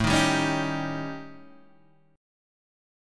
A 7th Flat 5th